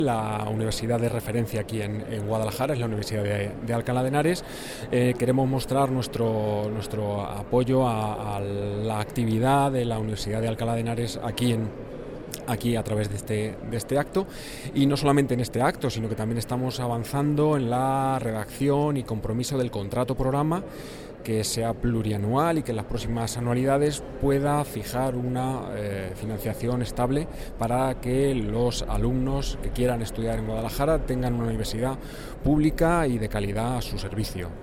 El director general de Universidades, Investigación e Innovación, Ricardo Cuevas, habla de la colaboración entre el Gobierno regional y la Universidad de Alcalá de Henares.